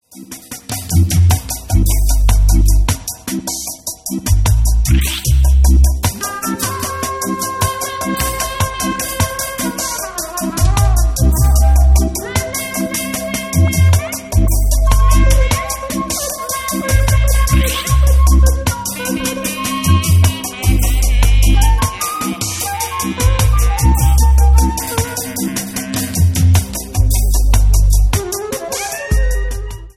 titres down tempo
flûte traversière